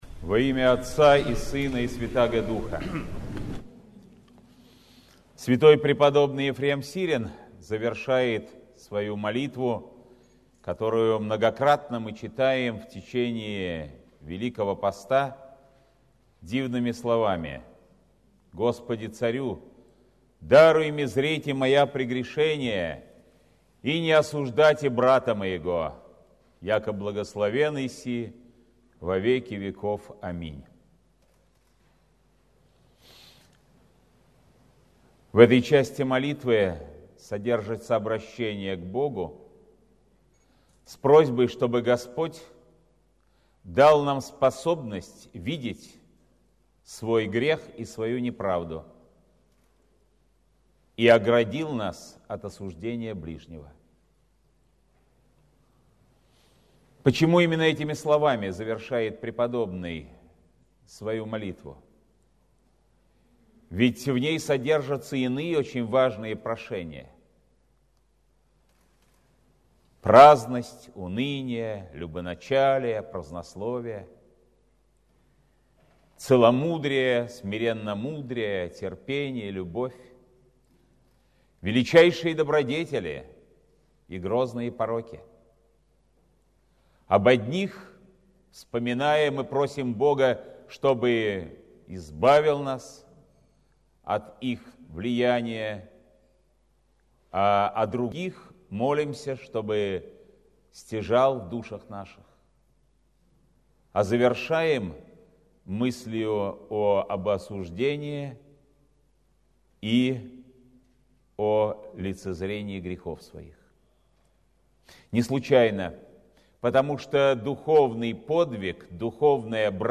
Сретенский монастырь.
Слово Святейшего Патриарха Московского и всея Руси Кирилла